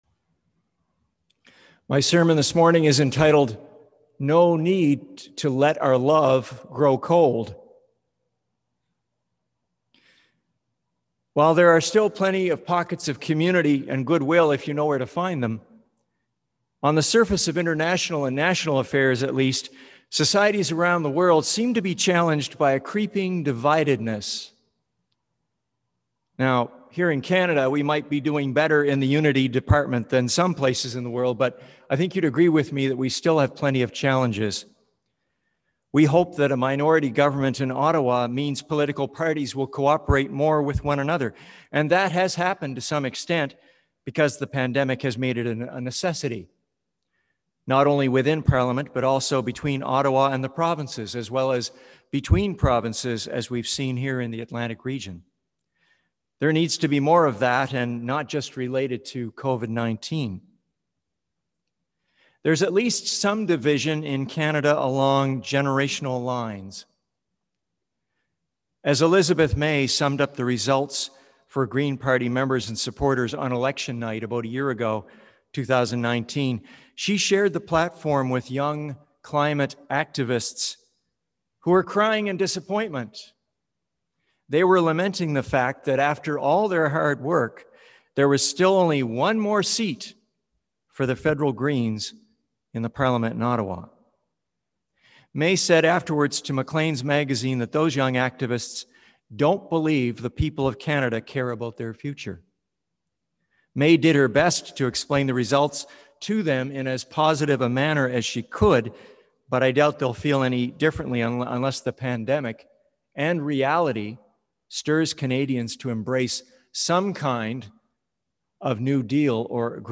Sermons
oct25sermon.mp3-.mp3